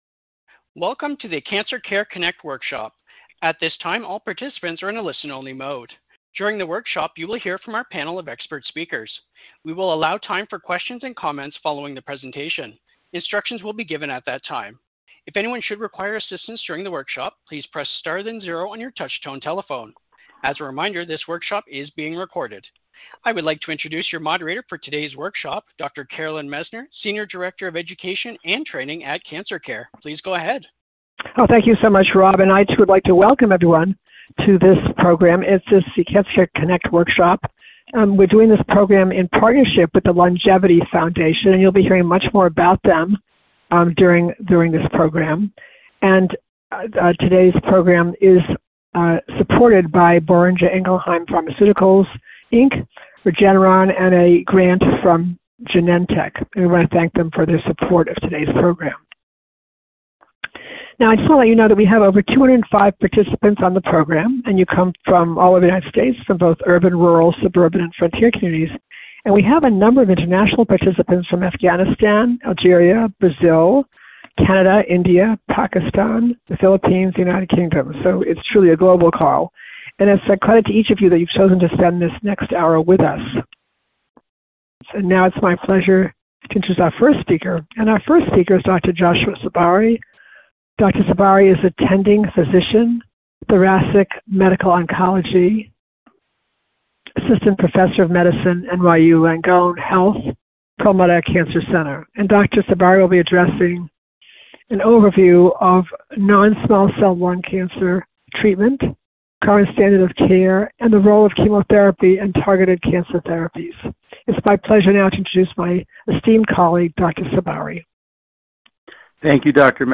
Questions for Our Panel of Experts
workshop